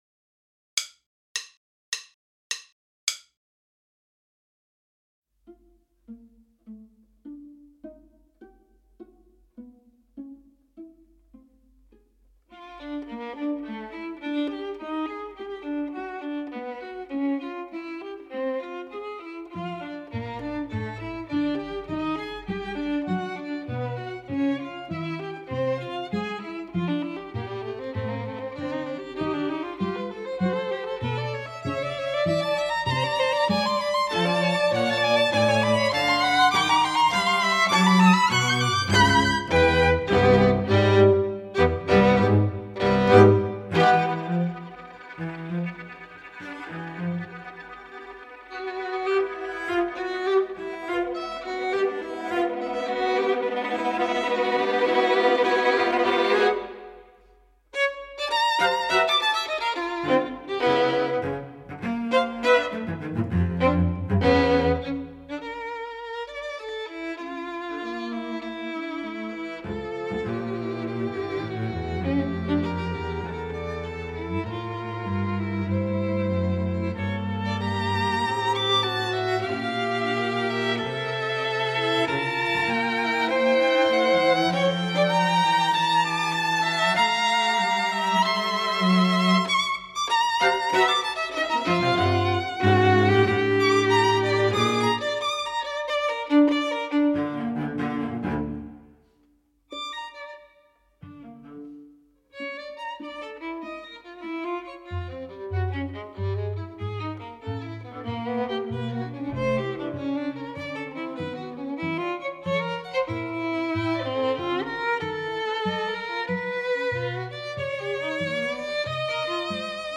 Minus Viola